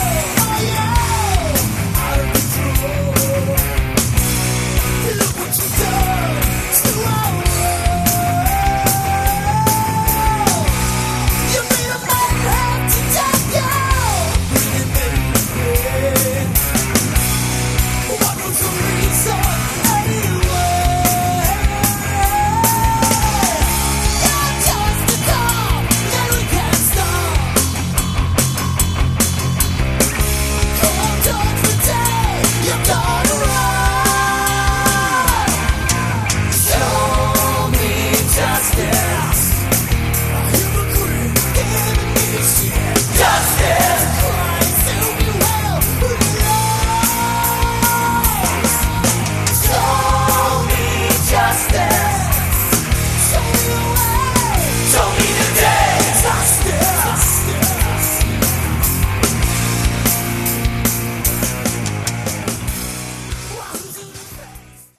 Category: Melodic Metal
vocals, guitar
vocals, bass
vocals, guitar, keyboards, saxophone
additional drums